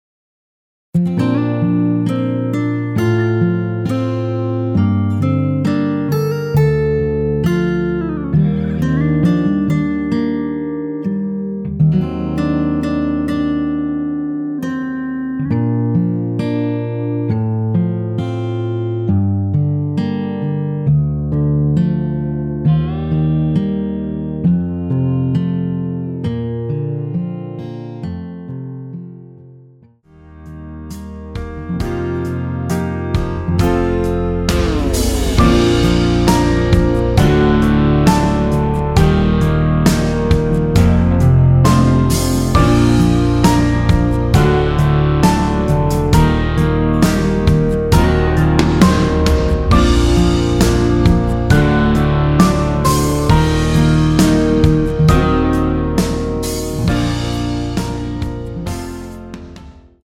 원키에서(-2) 내린 MR 입니다.
Bb
앞부분30초, 뒷부분30초씩 편집해서 올려 드리고 있습니다.
중간에 음이 끈어지고 다시 나오는 이유는